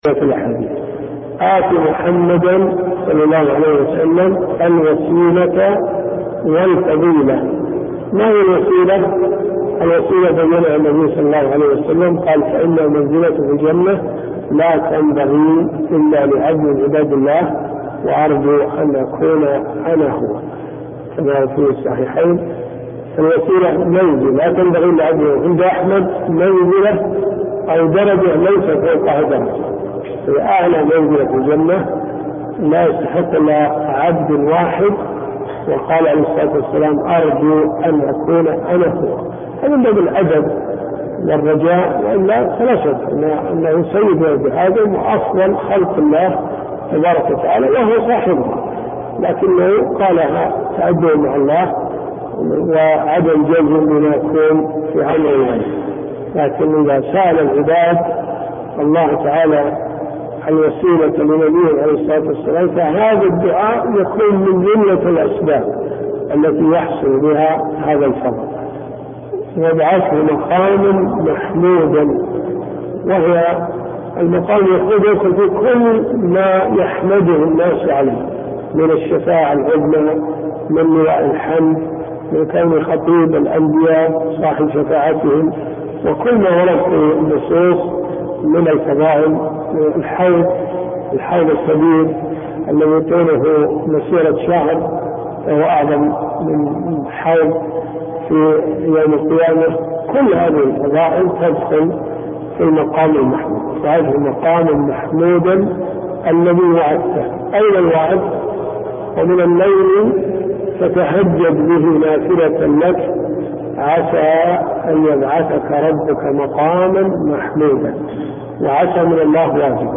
الدروس الشرعية
دروس صوتيه ومرئية تقام في جامع الحمدان بالرياض